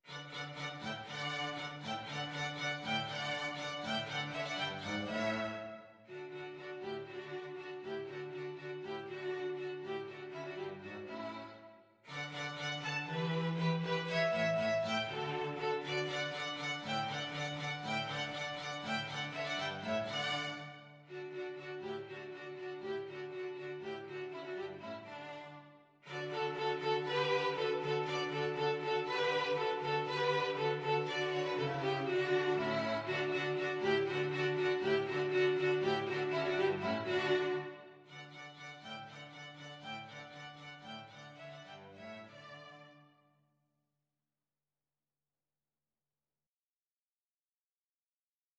Allegro (View more music marked Allegro)
String trio  (View more Easy String trio Music)
Classical (View more Classical String trio Music)